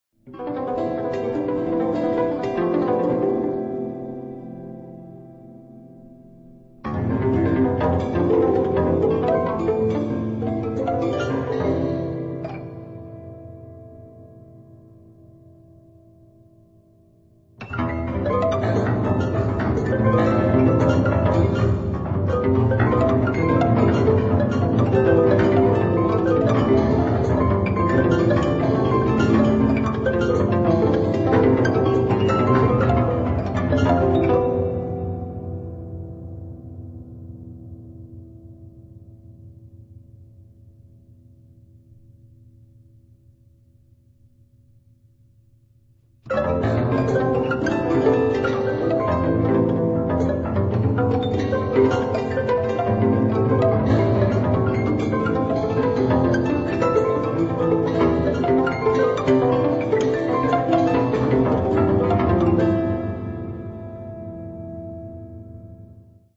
prepared-piano work